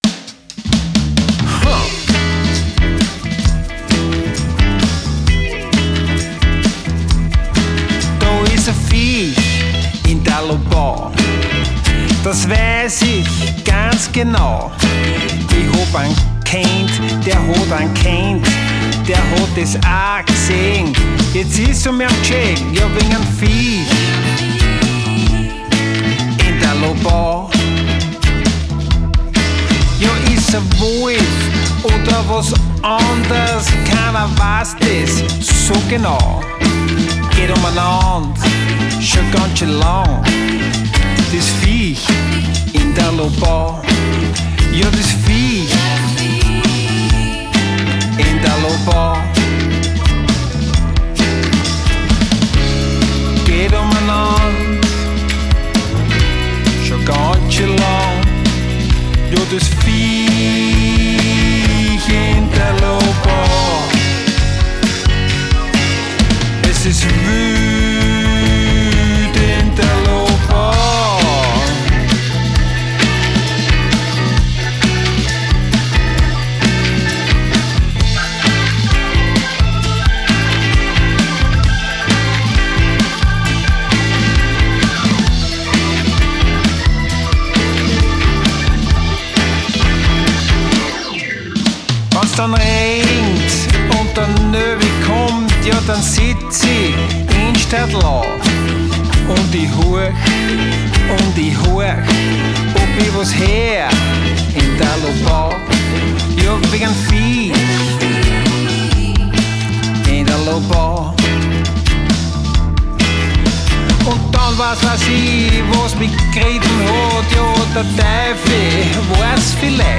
Bassgitarre
Dobro PERCUSSION
TROMPETE